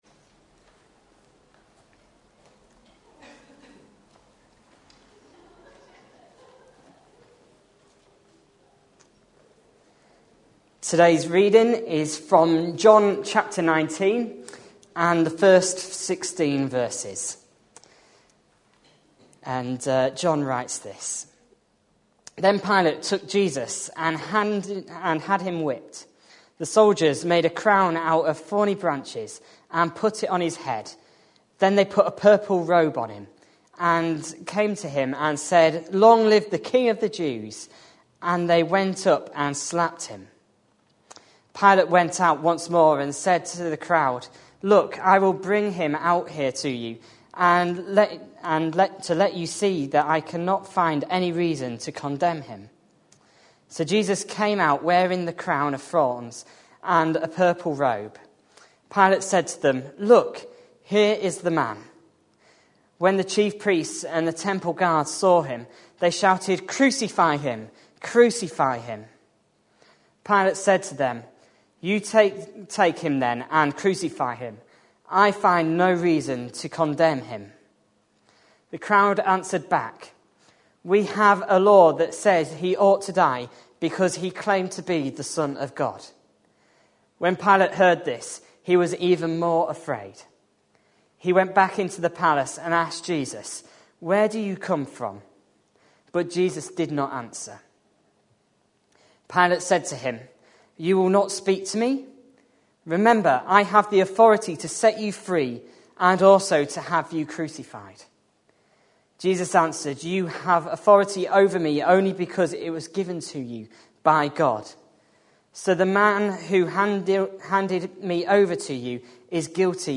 A sermon preached on 10th March, 2013, as part of our Passion Profiles and Places -- Lent 2013. series.